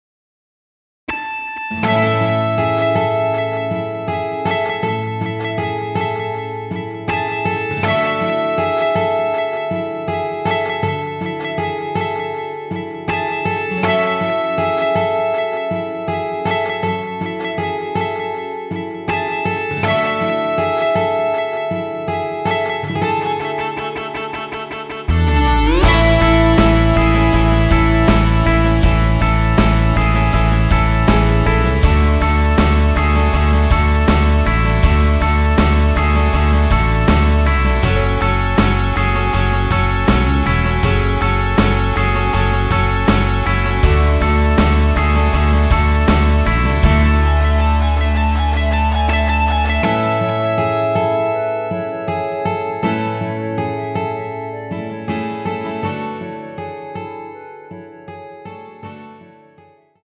원키에서(+5)올린 멜로디 포함된 MR입니다.
앞부분30초, 뒷부분30초씩 편집해서 올려 드리고 있습니다.